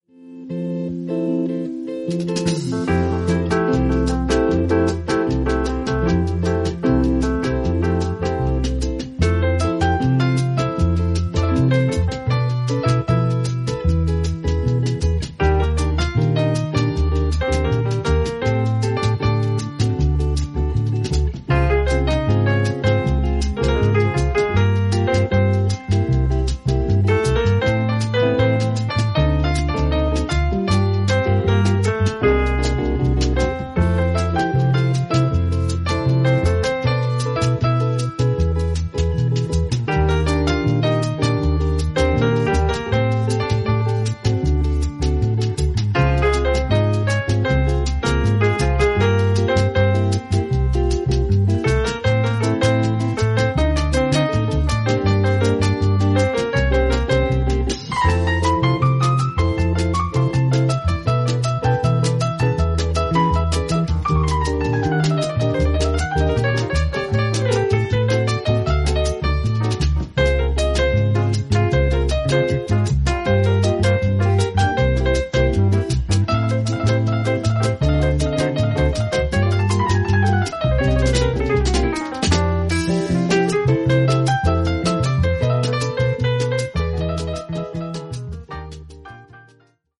イタリアのライブラリーらしく、メロディが綺麗なボッサやソフトロックも良いですね。